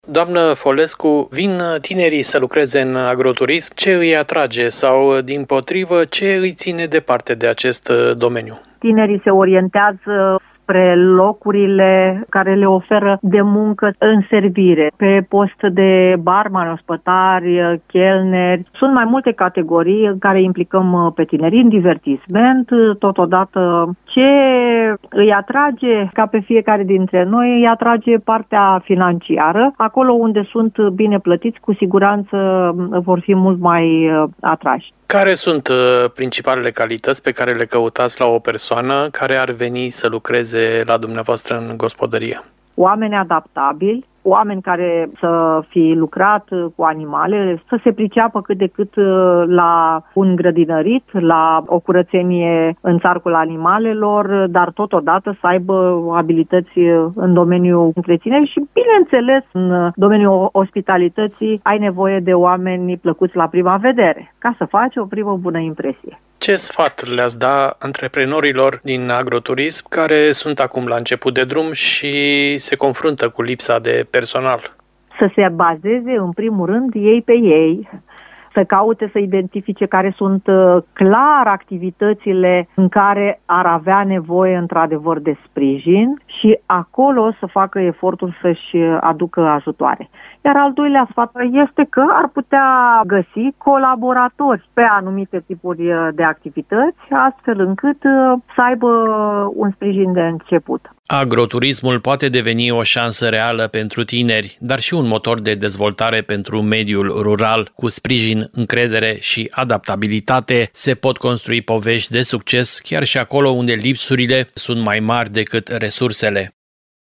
despre provocările turismului rural.